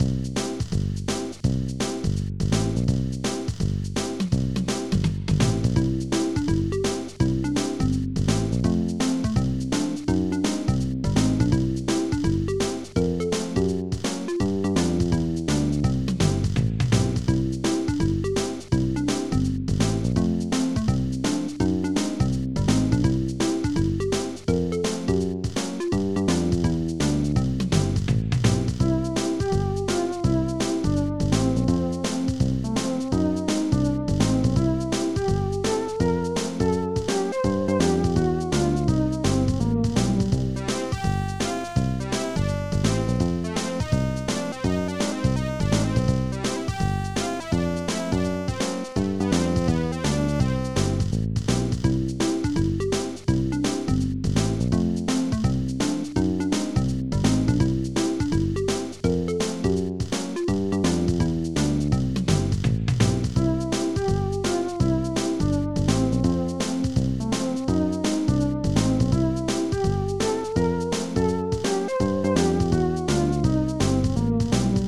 Protracker and family
ST-88:gbass
st-08:IT! Snare 6
st-08:IT! Bassdrum 2
st-01:shaker
st-04:tom2
st-01:korgstring